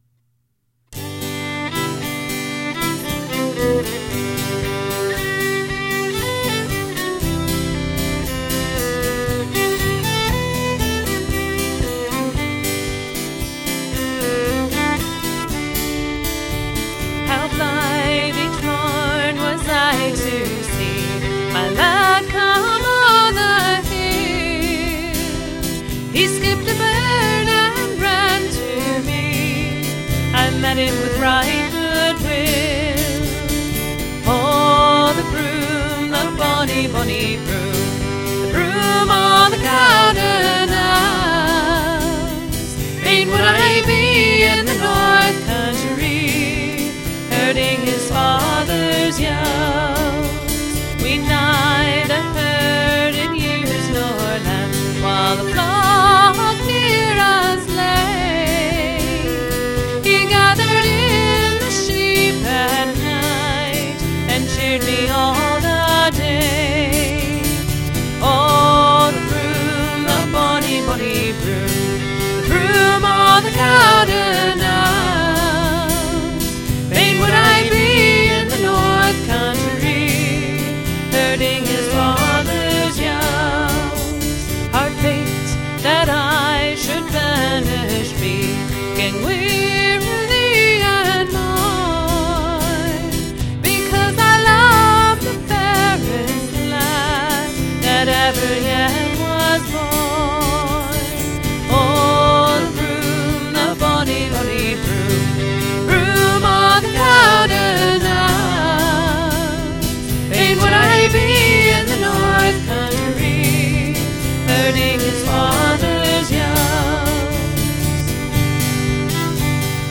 This Greenville Irish Band is a traditional Irish band based in South Carolina.